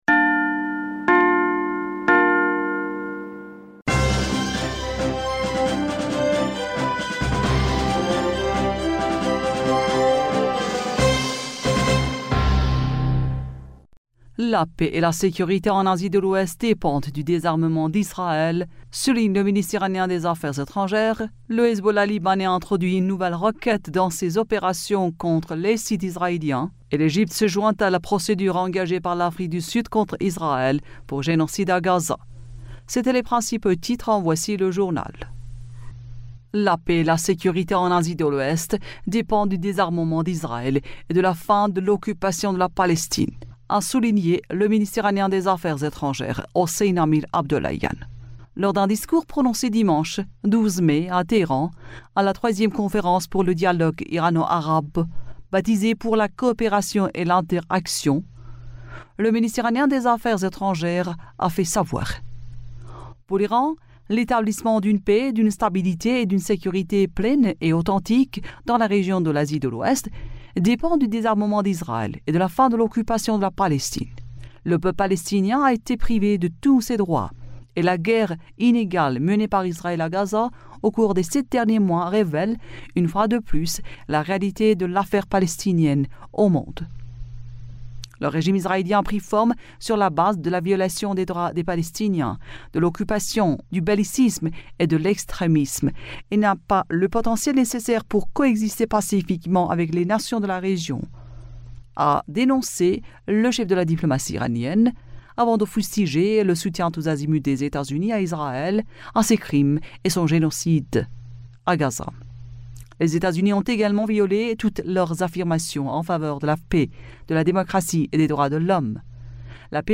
Bulletin d'information du 13 Mai